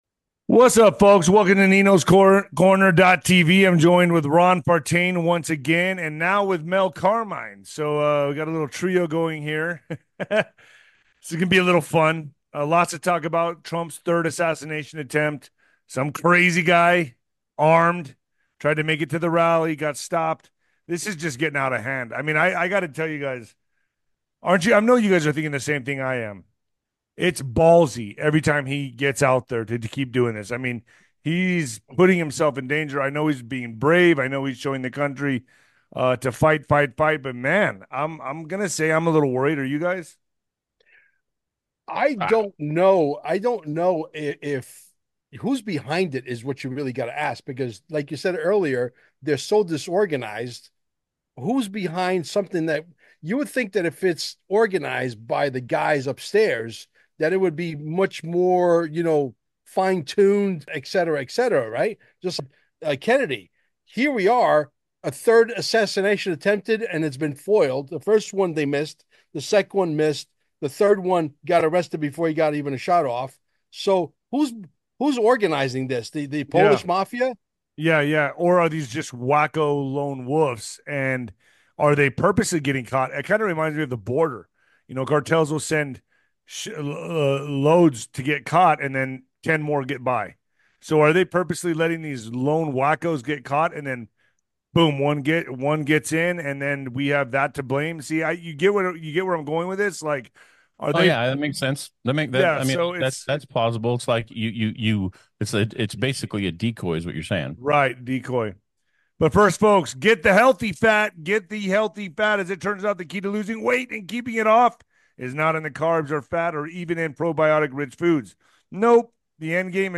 The conversation circles back to the suspect's background and the unlikely success of his plan. Tune in for an engaging discussion.